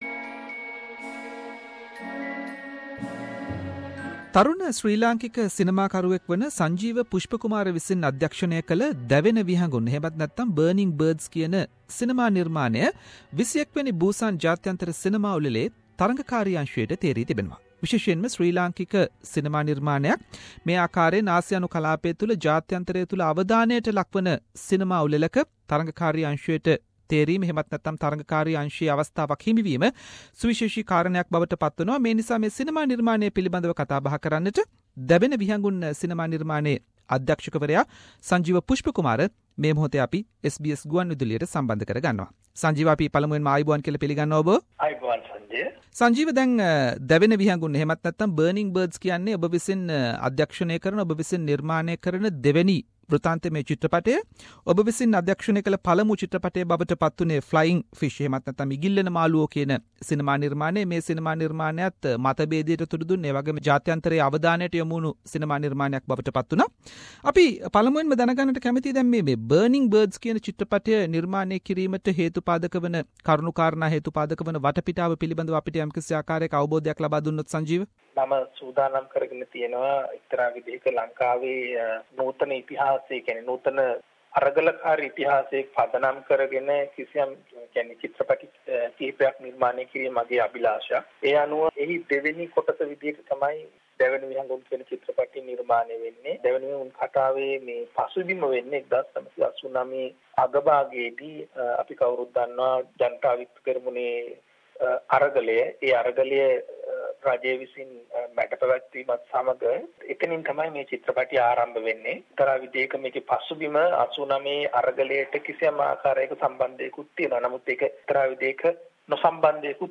SBS Sinhalese interviewed